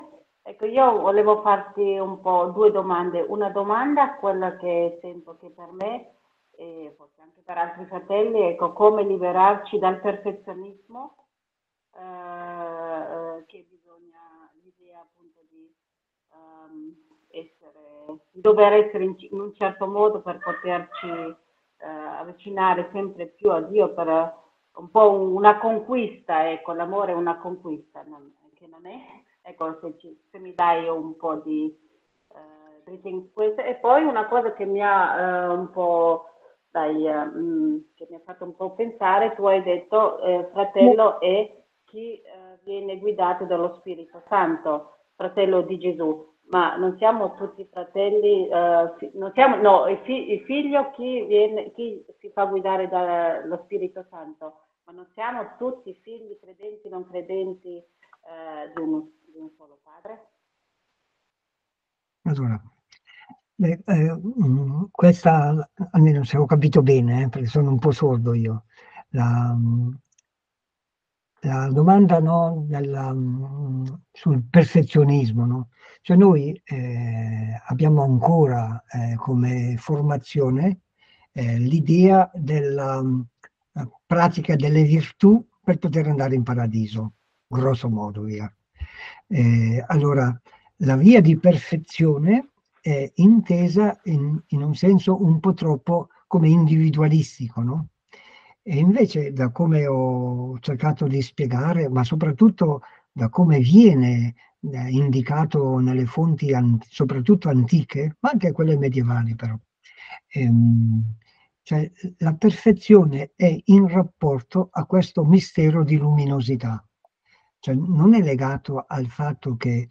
Meditazione/incontro via “zoom” per la Comunità Giovanni XXIII di Rimini – 18 maggio 2024